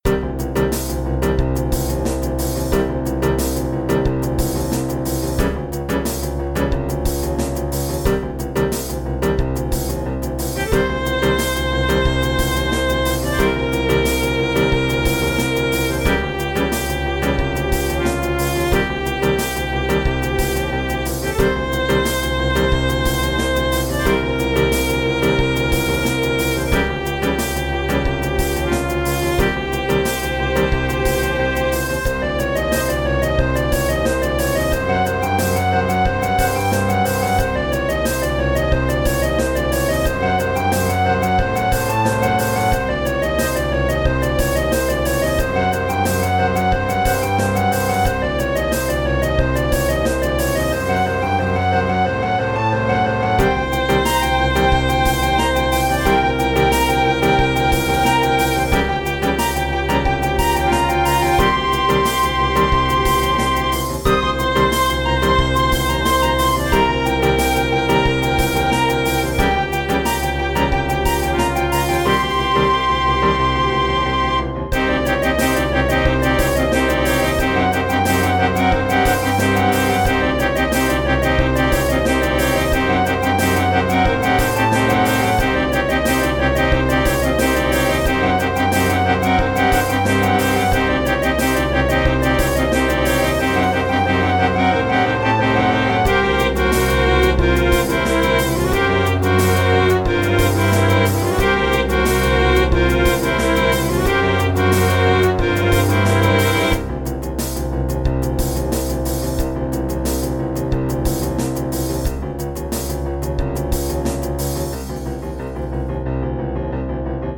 Sounds like something you could have for a villain!